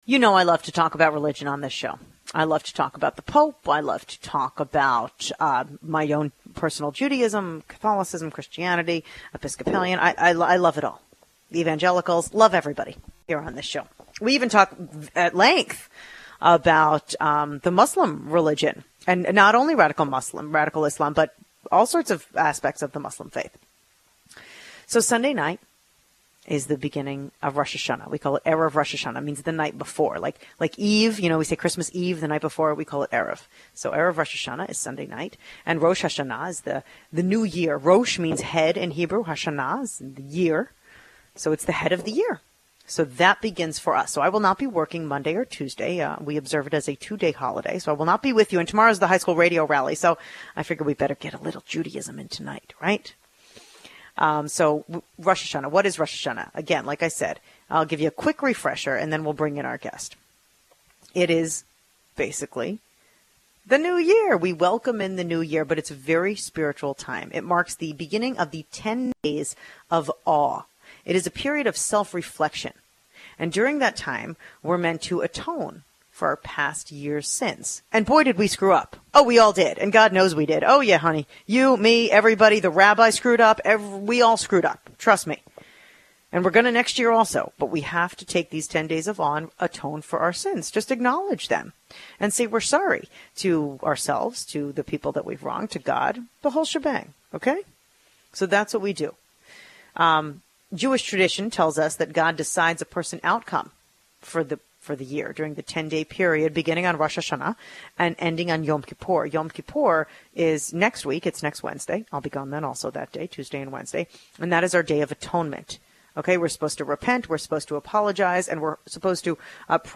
Radio Guest Spots